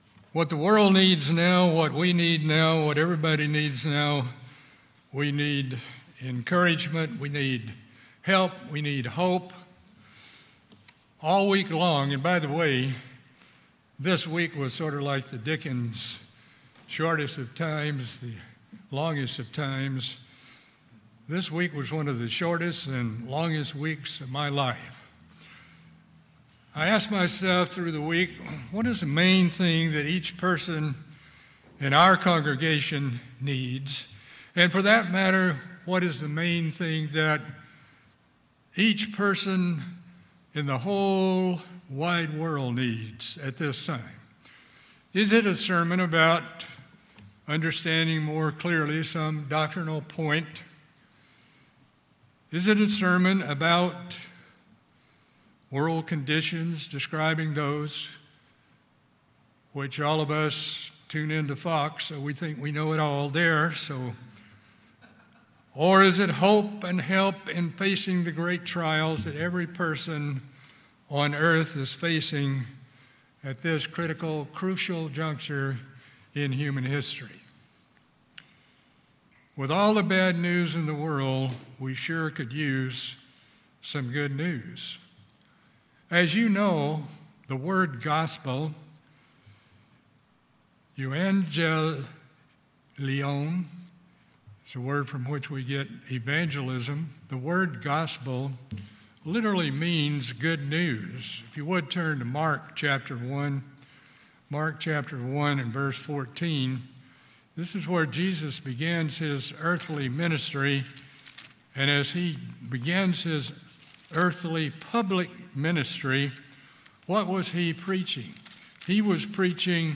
In this sermon we examine the keys to overcoming anxious care, fear, doubt, superstition and the dogmas of man. Emphasis is placed on understanding the sure promises of God and His care and concern for each person who has ever live or ever will live.